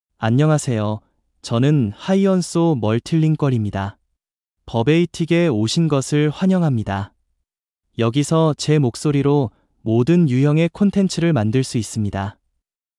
Hyunsu MultilingualMale Korean AI voice
Hyunsu Multilingual is a male AI voice for Korean (Korea).
Voice sample
Listen to Hyunsu Multilingual's male Korean voice.
Hyunsu Multilingual delivers clear pronunciation with authentic Korea Korean intonation, making your content sound professionally produced.